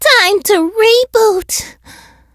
meg_die_vo_06.ogg